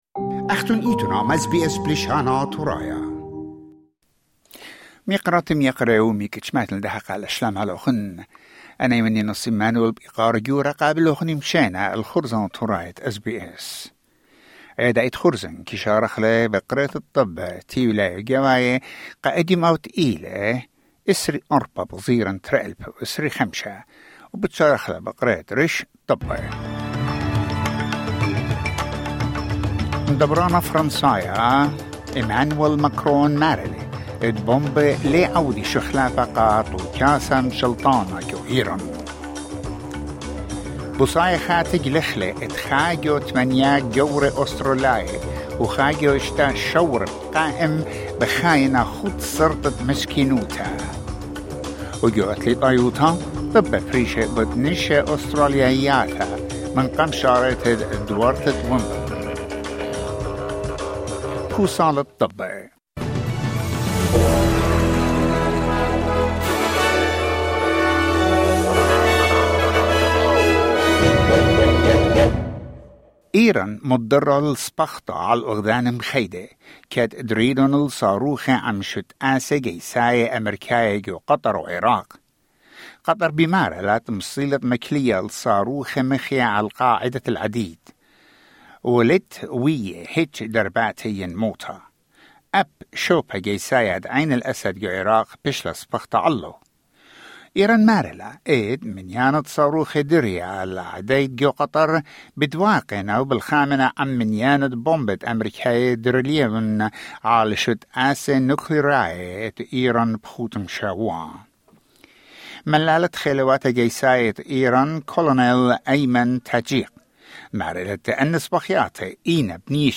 SBS Assyrian news bulletin: 24 June 2024